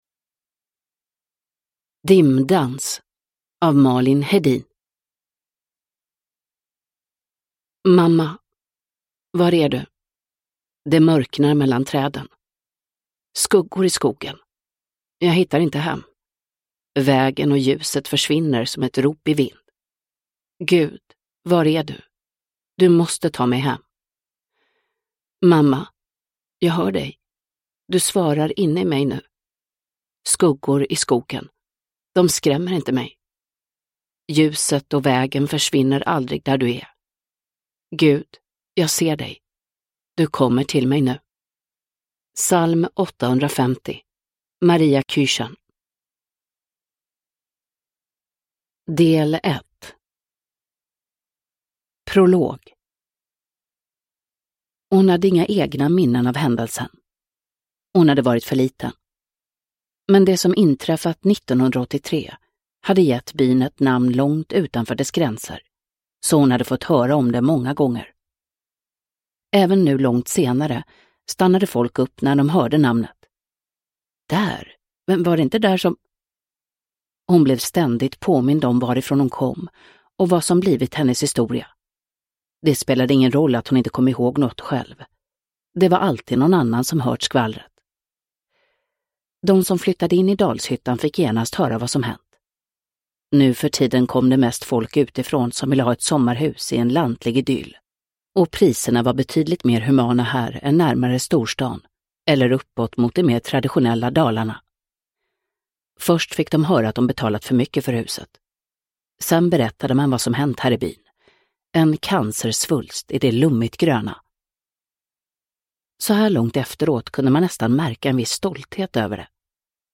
Dimdans – Ljudbok – Laddas ner
Uppläsare: Mirja Turestedt